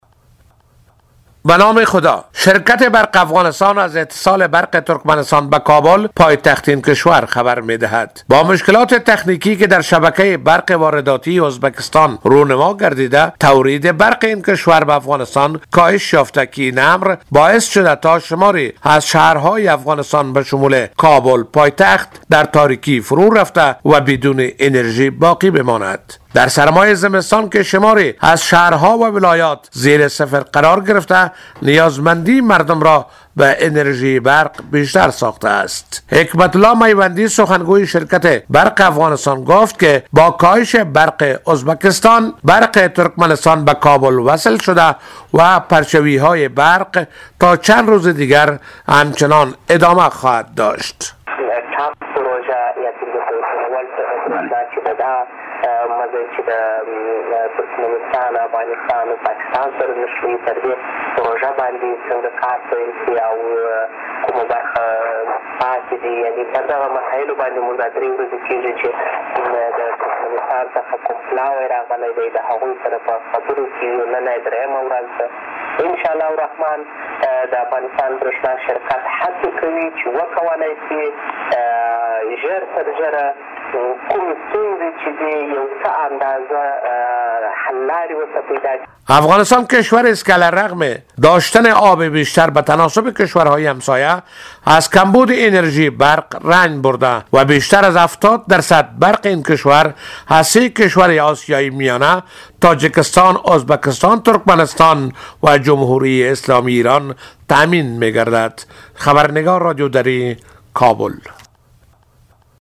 گزارش تکمیلی از خبرنگار رادیو دری